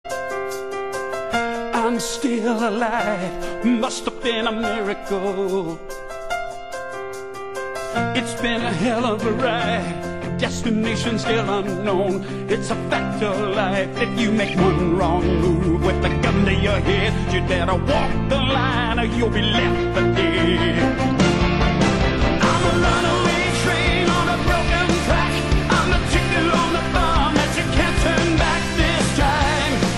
opera-like voice and sound